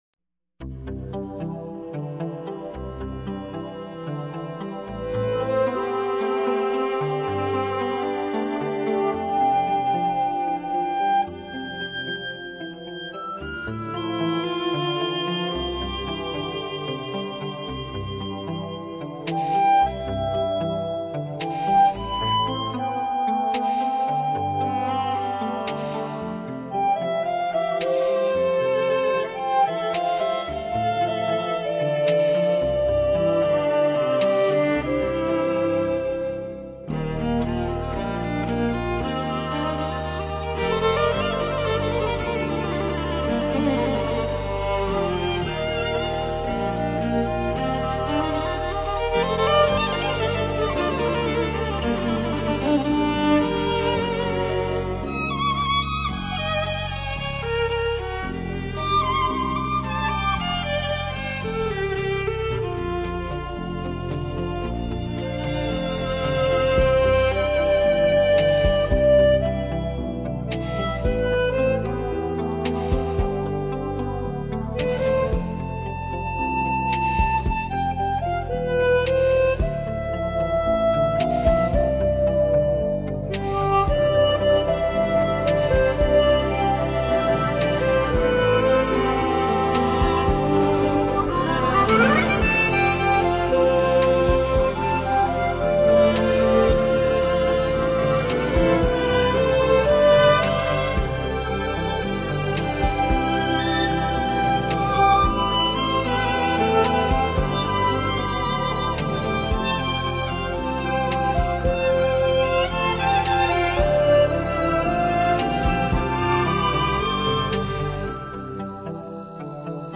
飘然的口琴加天籁的人声,天地之间,
一种沁人心脾的清冽和甘甜,舒缓了紧张的神经,